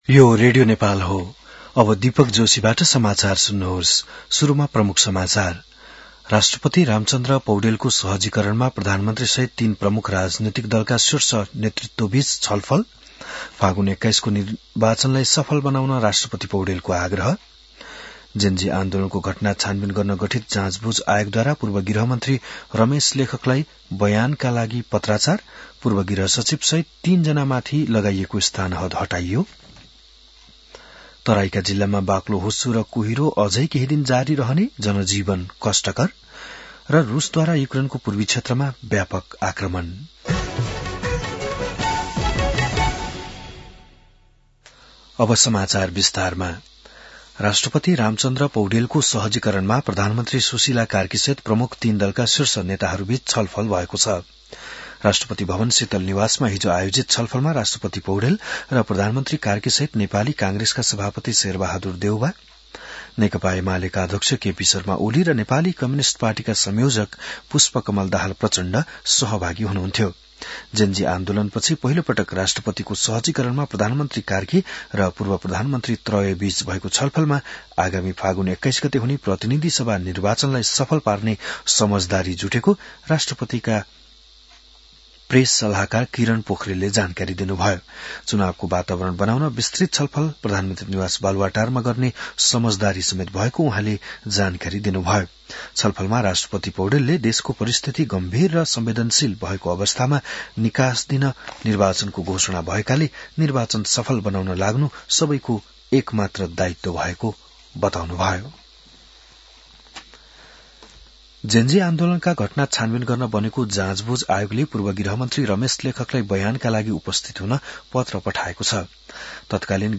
बिहान ९ बजेको नेपाली समाचार : ९ पुष , २०८२